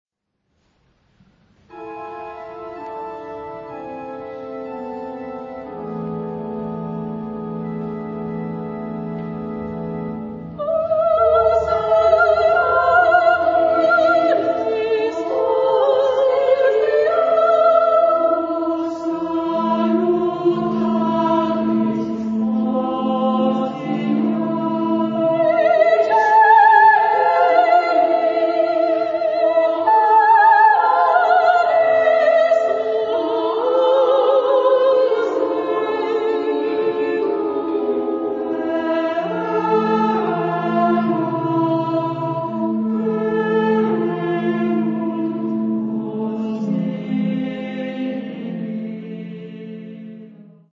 Género/Estilo/Forma: Sagrado ; Motete
Carácter de la pieza : moderado
Tipo de formación coral: SSA  (3 voces Coro femenino )
Instrumentación: Organo  (1 partes instrumentales)
Tonalidad : la menor